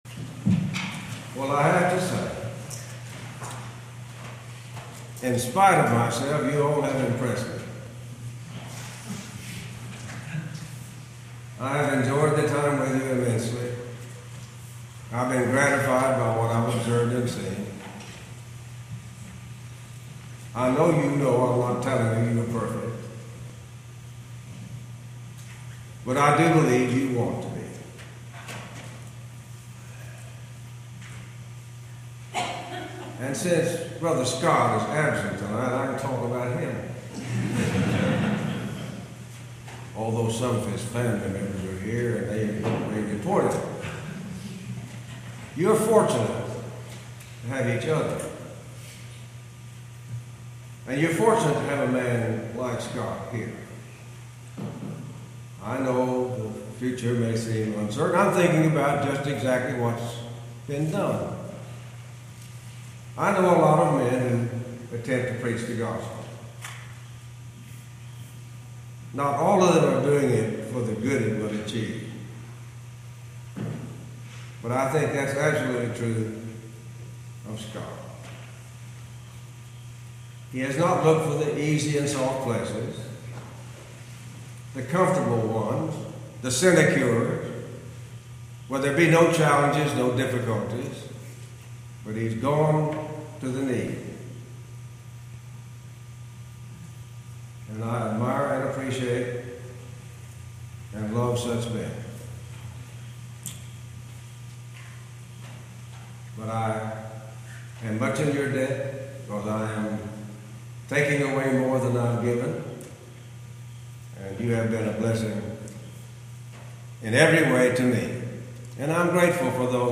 Series: Gettysburg 2013 Gospel Meeting